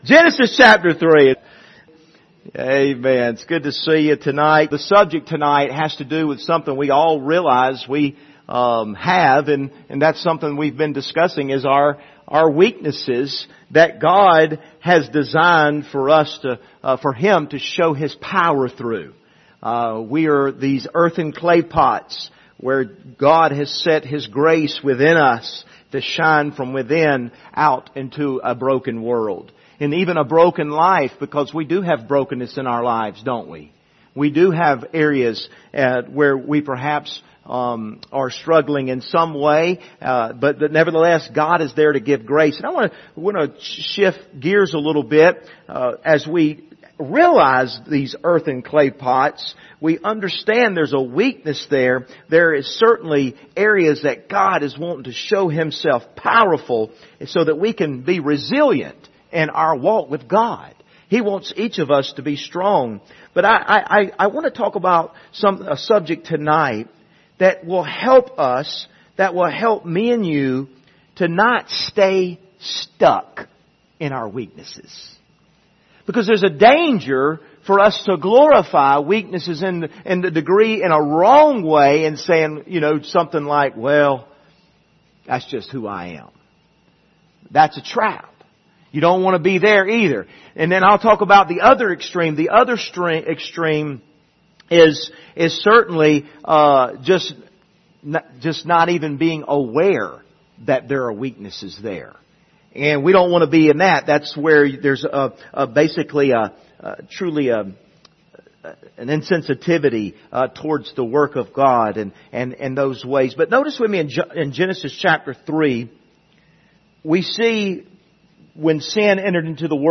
Service Type: Wednesday Evening Topics: repentance